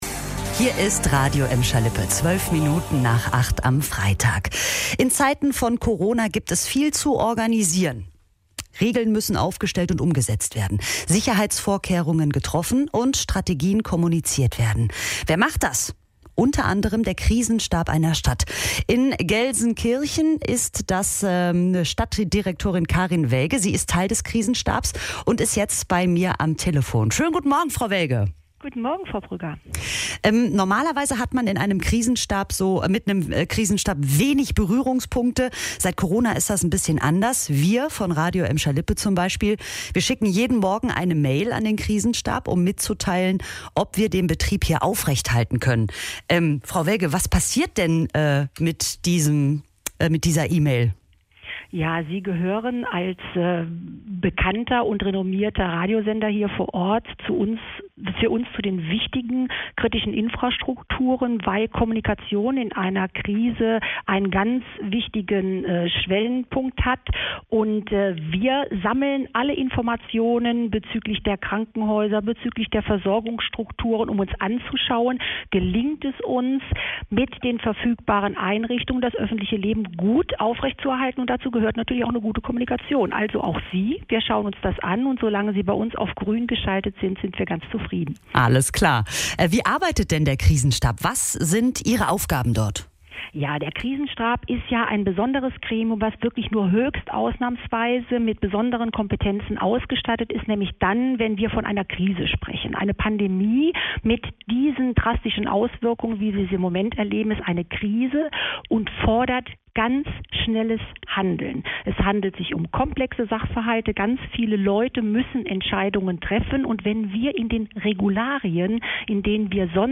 Stadtdirektorin Karin Welge bei uns im Gespräch - Radio Emscher Lippe
Wir haben mit Karin Welge, Stadtdirektorin in Gelsenkirchen und Teil des Krisenstabs, gesprochen: